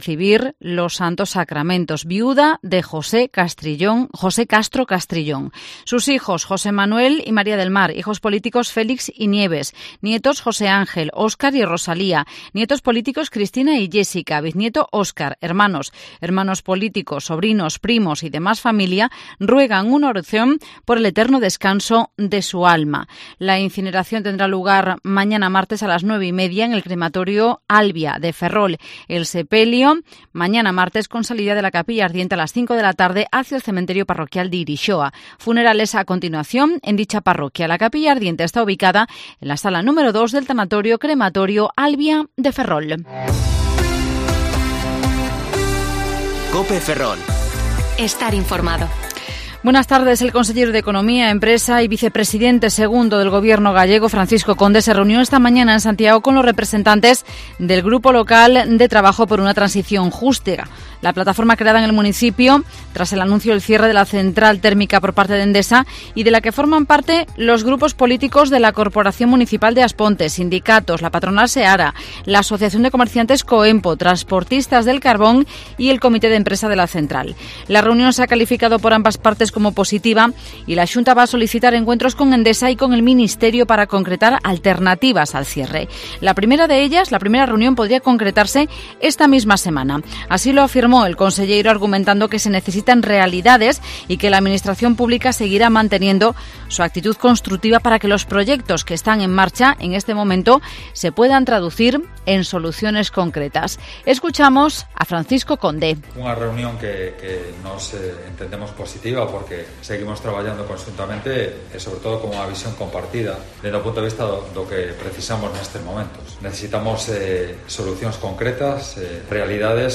Informativo Mediodía COPE Ferrol 19/07/2021.